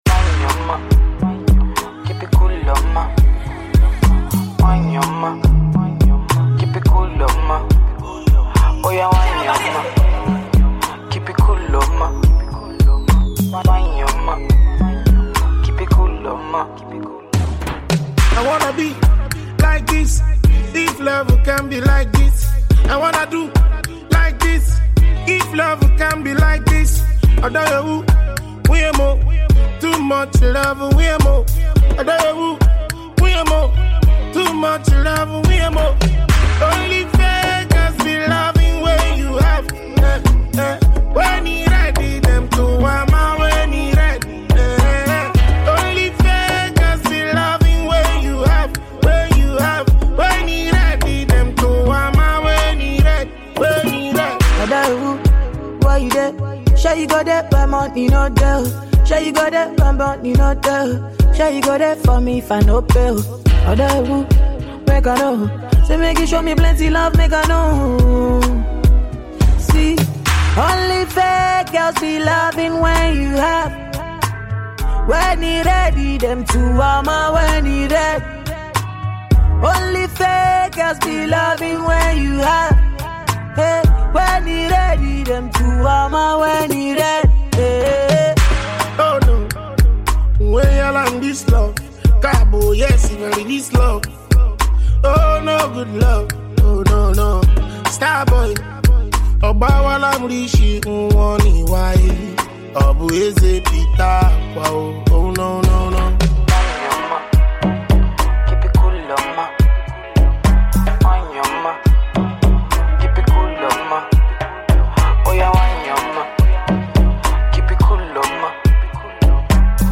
highlife-tinged banger